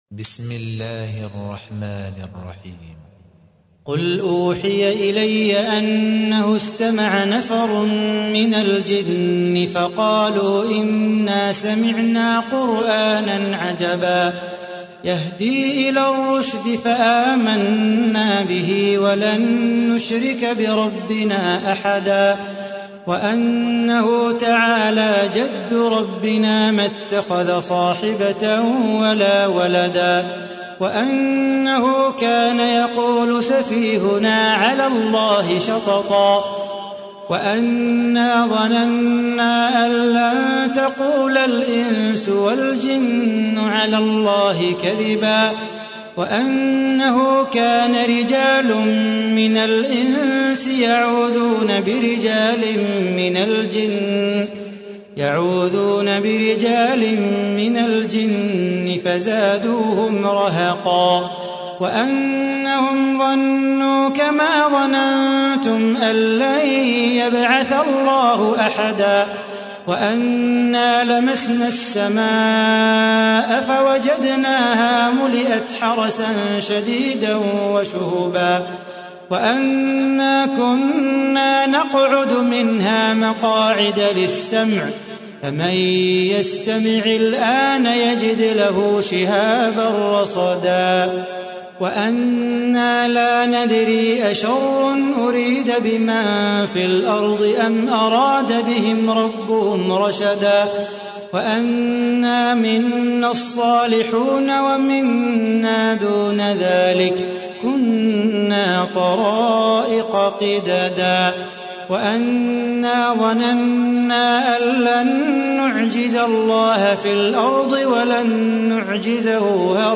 تحميل : 72. سورة الجن / القارئ صلاح بو خاطر / القرآن الكريم / موقع يا حسين